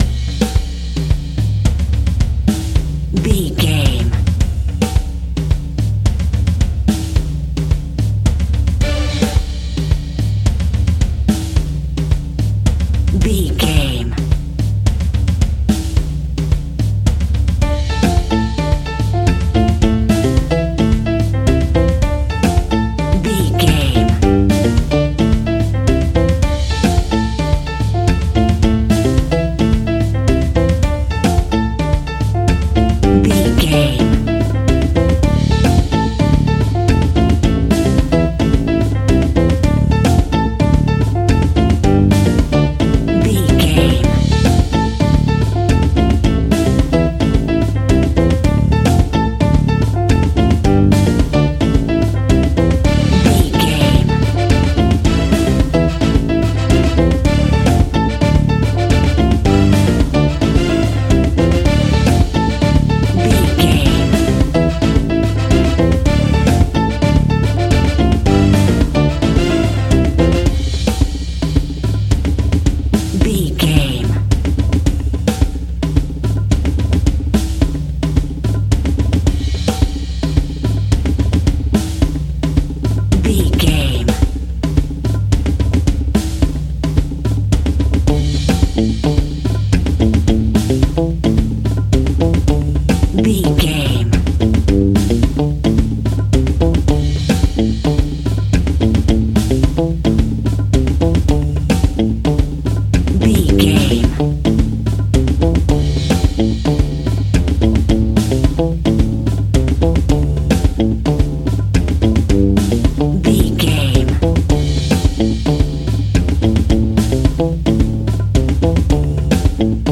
Aeolian/Minor
salsa
drums
bass guitar
electric guitar
piano
hammond organ
percussion